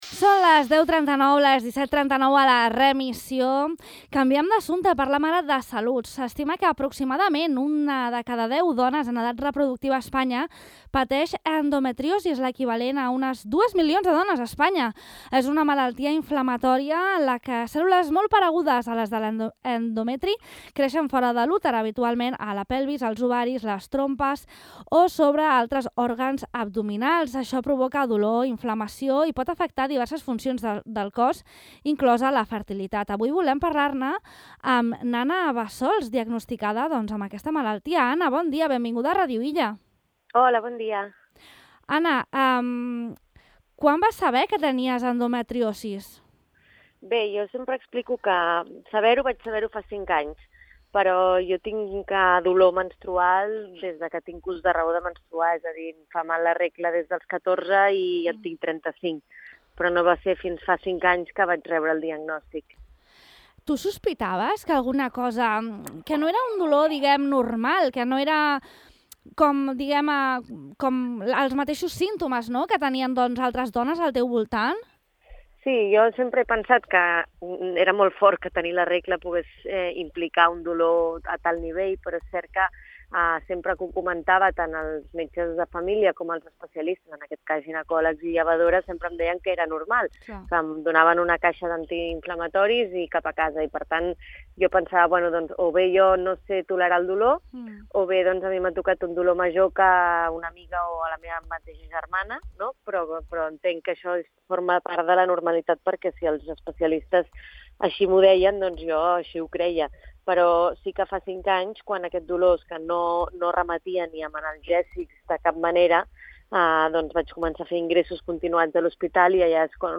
Per parlar d’aquesta realitat, avui al programa De Far a Far de Ràdio Illa hem entrevistat